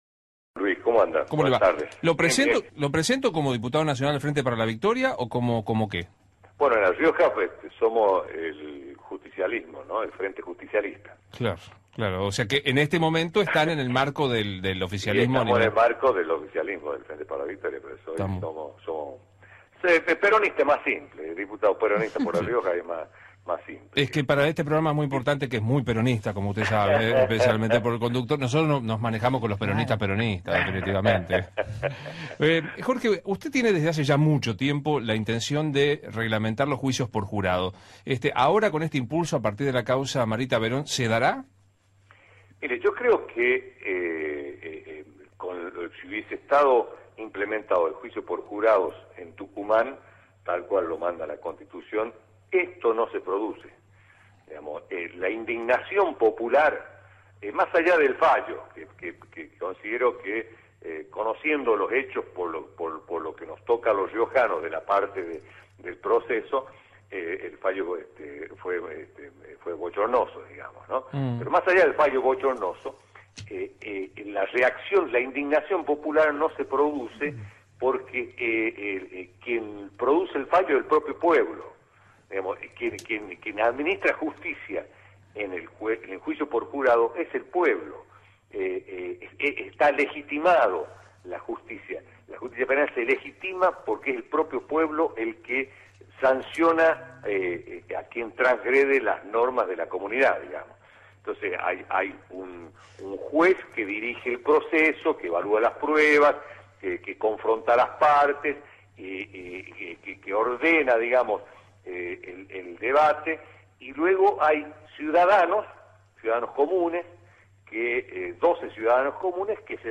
Jorge Yoma, diputado nacional, por Radio 10
«El Gobierno está administrando un país que tiene más de 25% de inflación, que está peleado comercialmente con 40 países del mundo por denuncias de proteccionismo y problemas comerciales con todo el mundo. Esto le suma más problemas al Ejecutivo», opinó el diputado del Frente para la Victoria en diálogo con Radio 10.